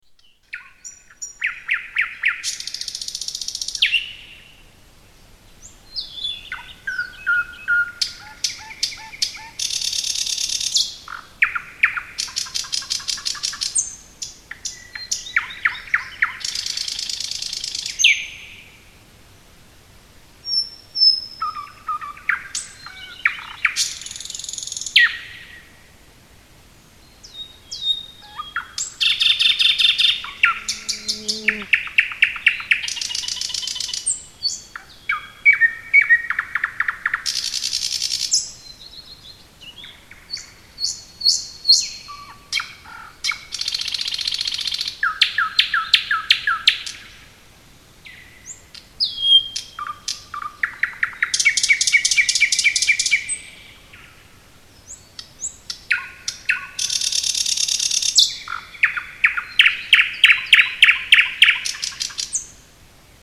Nighting gale
Tags: Travel Estonia Sounds of Estonia Europe Republic of Estonia